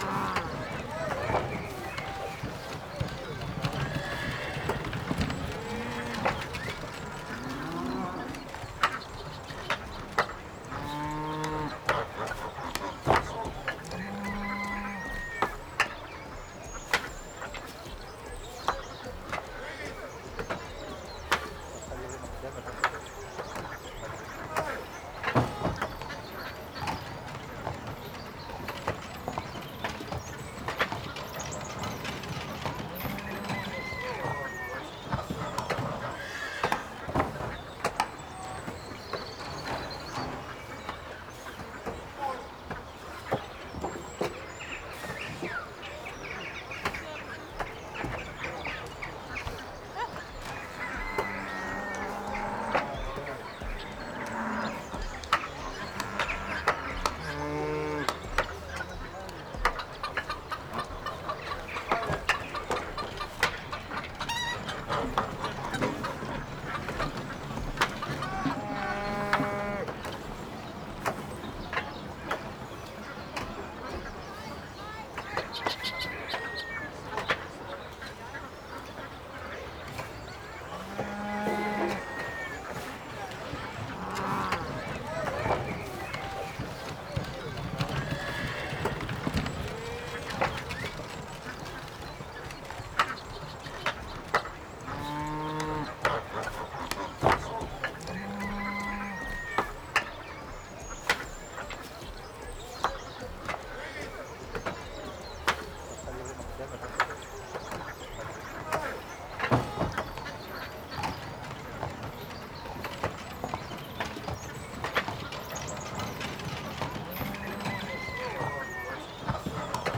Village Center.ogg